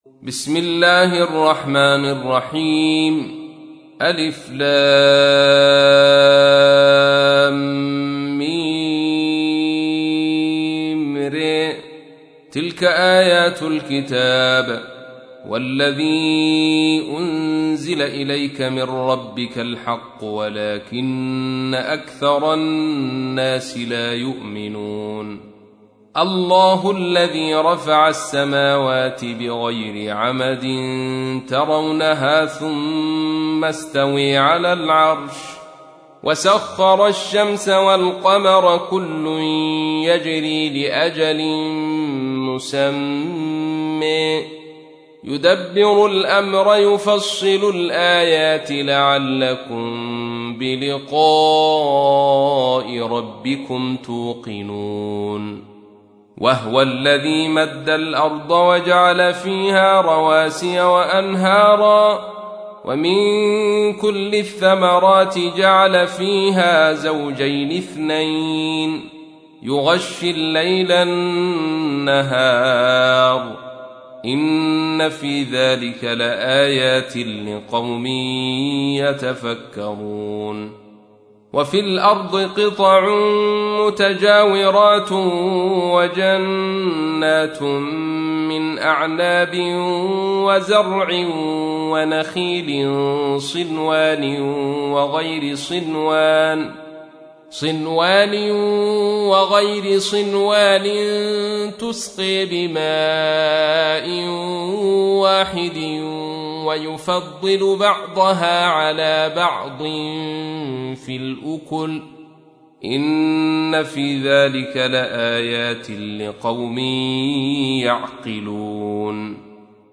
تحميل : 13. سورة الرعد / القارئ عبد الرشيد صوفي / القرآن الكريم / موقع يا حسين